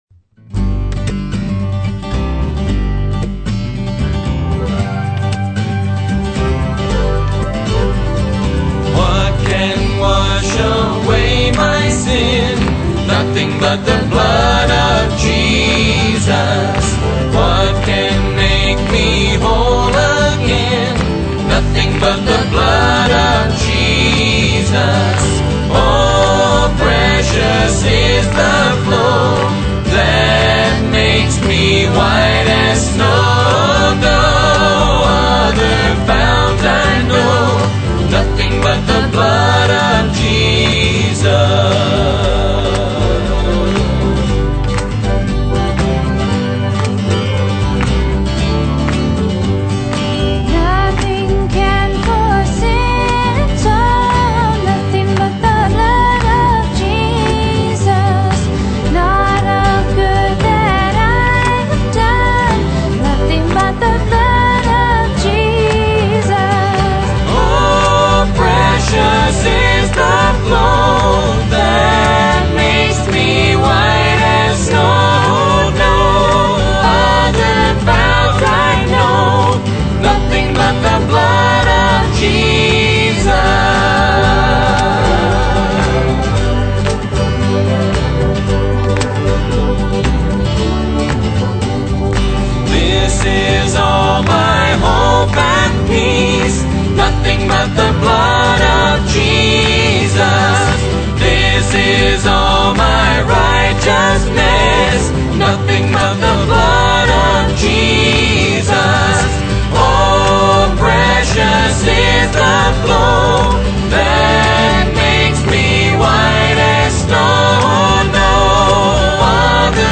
Traditional vocal (